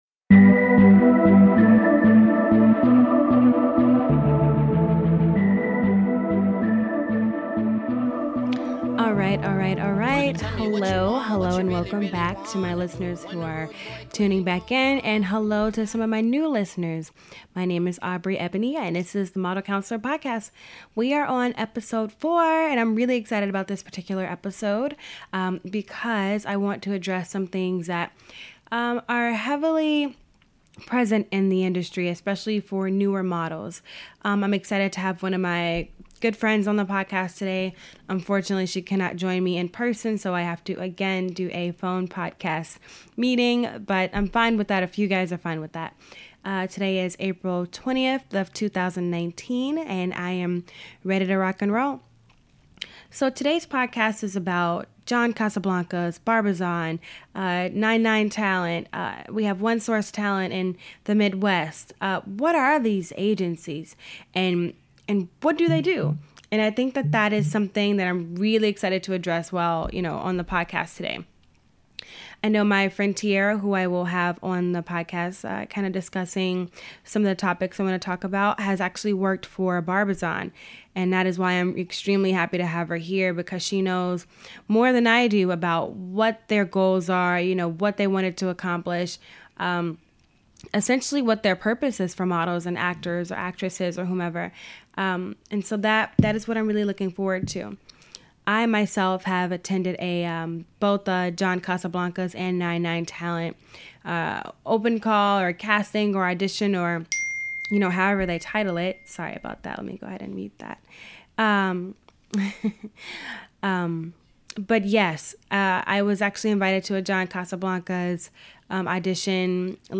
For starters I want to apologize for the parts of this podcast where the signal gets a bit staticy.